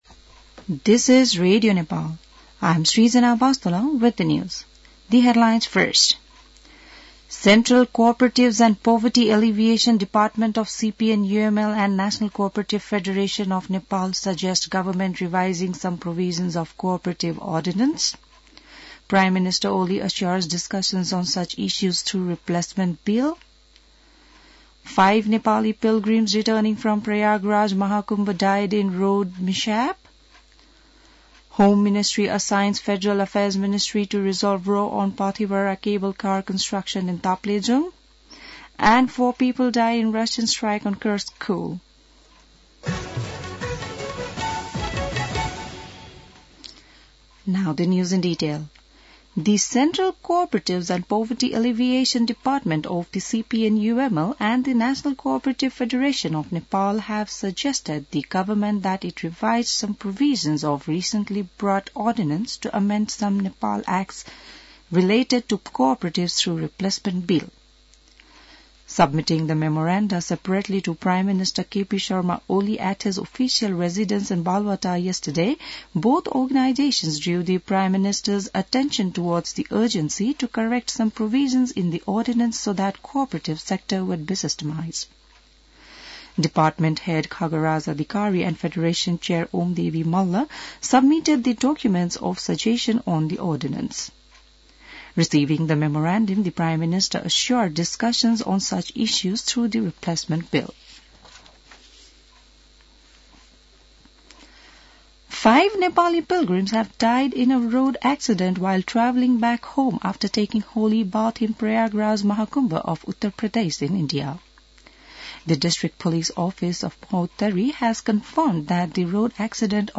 बिहान ८ बजेको अङ्ग्रेजी समाचार : २१ माघ , २०८१